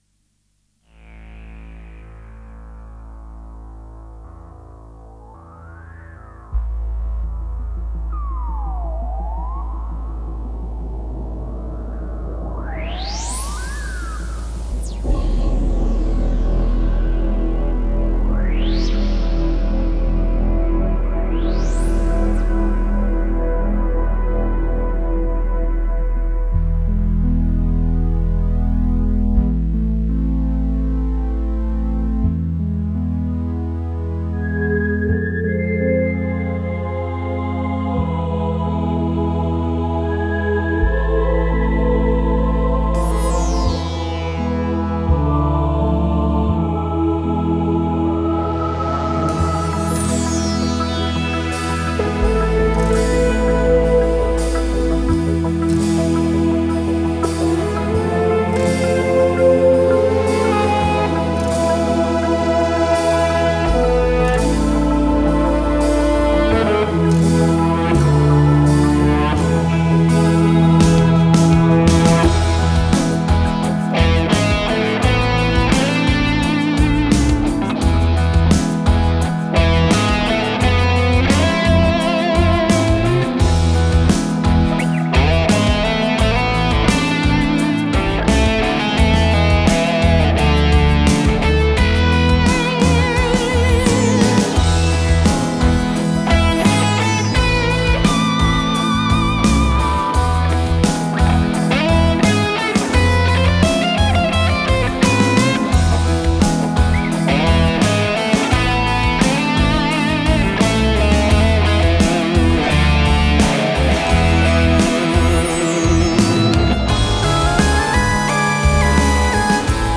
My epic prog tune LOL, mix opinions please! E Phrygian Dominant of course!